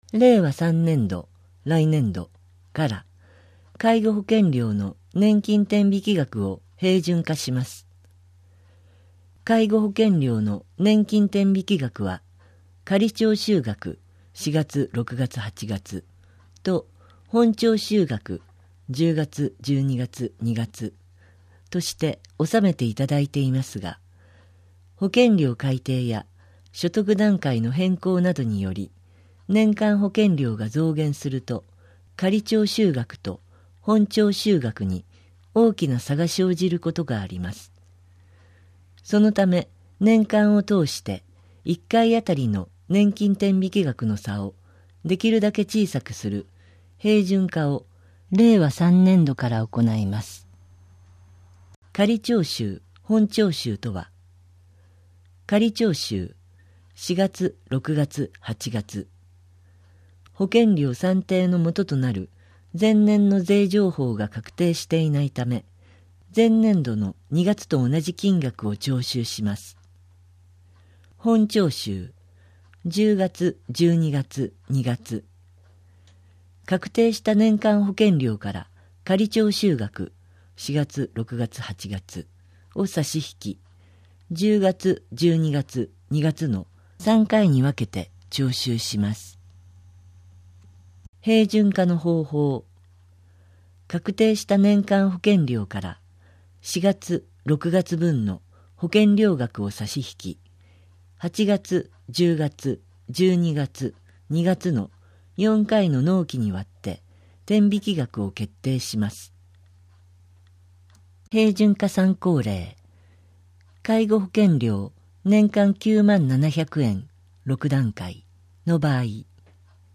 こちらではMP3版の声の広報を、項目ごとに分けて配信しています。